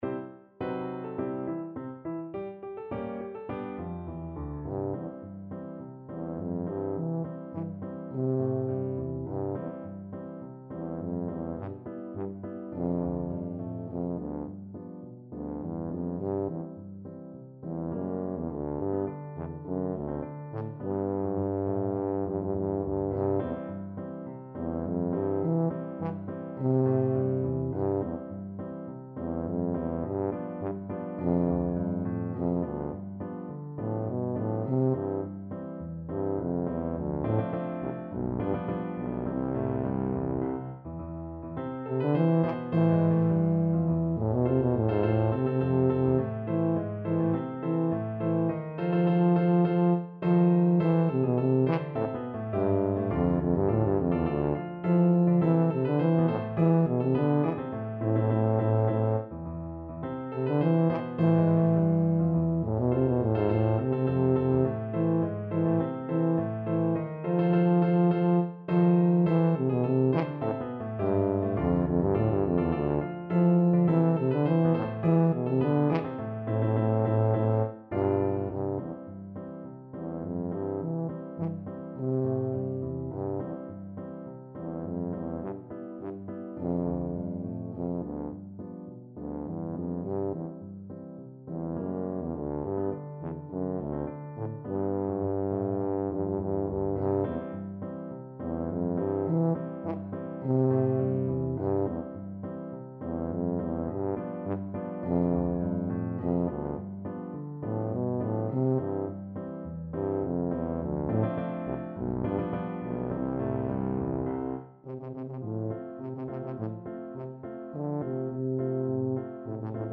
Quick March = c.104
C major (Sounding Pitch) (View more C major Music for Tuba )
Classical (View more Classical Tuba Music)